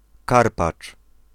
Summary Description Pl-Karpacz.ogg English: Polish native pronunciation of « KARPACZ » . Male voice.
Pl-Karpacz.ogg